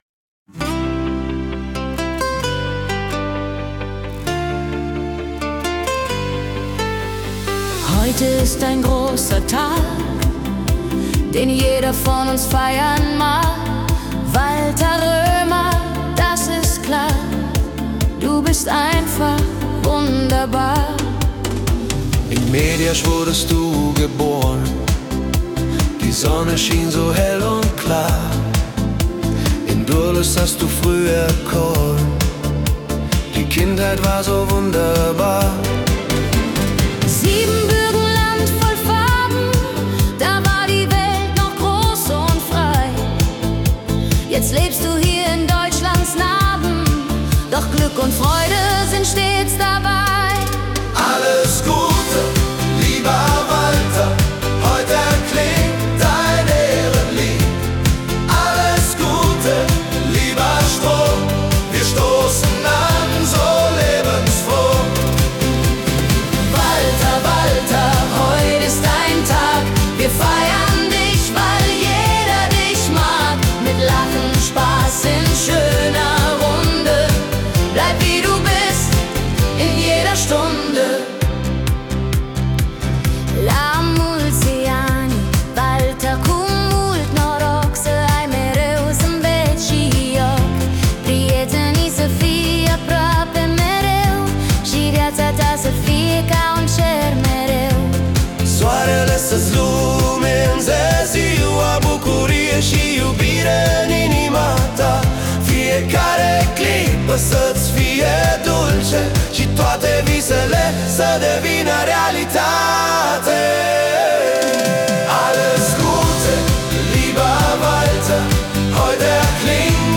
Schlager
• KI-generierte Melodie